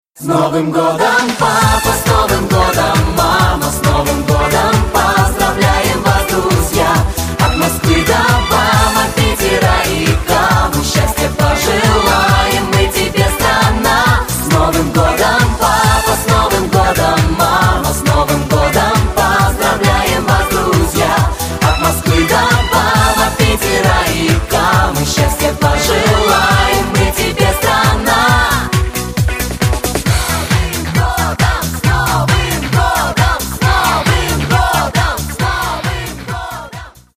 Новогодние Рингтоны
Поп Рингтоны